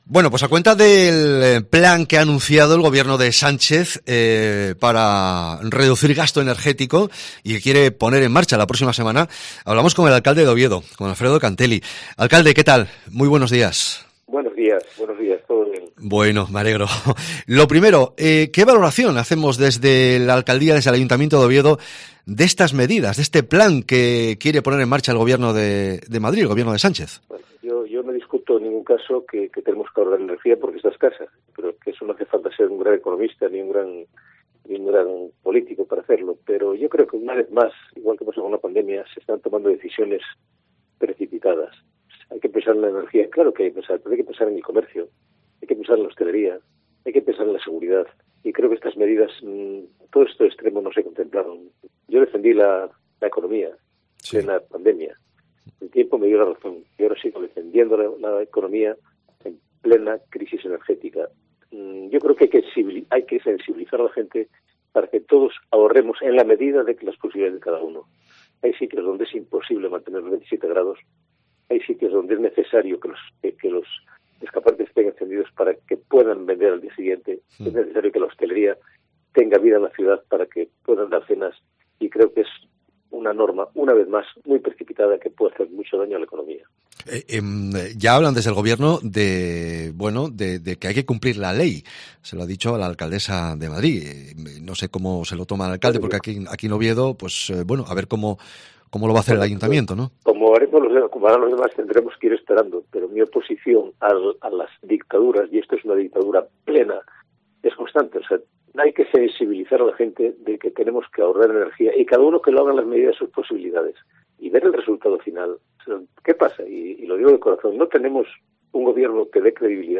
El alcalde de Oviedo, Alfredo Canteli, habla en COPE Asturias alto y claro a cuenta del plan de ahorro energético aprobado por el Gobierno de Pedro Sánchez que tanta polémica está provocando.